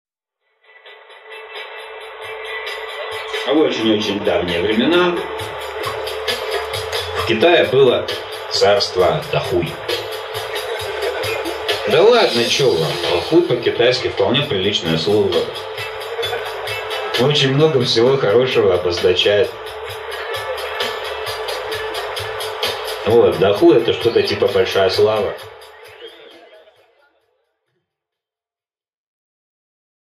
Аудиокнига Про сети с трёх сторон | Библиотека аудиокниг